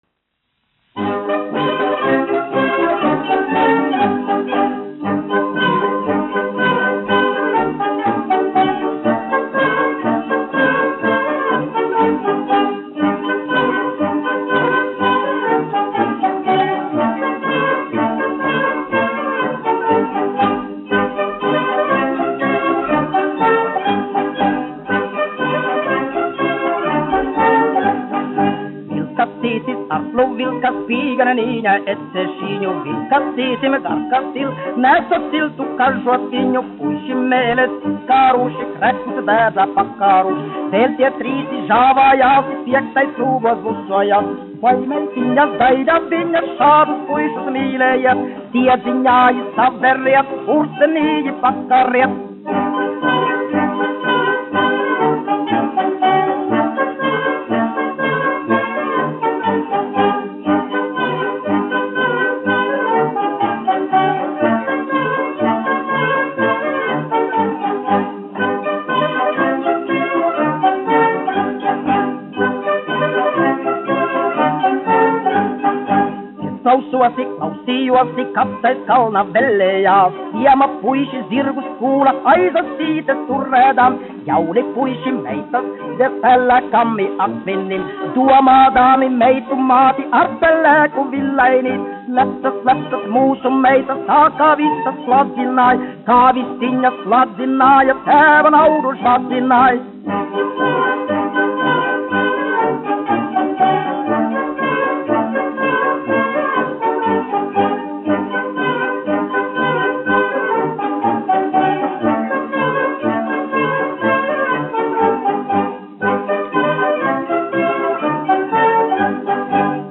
1 skpl. : analogs, 78 apgr/min, mono ; 25 cm
Polkas
Tautas mūzika -- Latvija
Latvijas vēsturiskie šellaka skaņuplašu ieraksti (Kolekcija)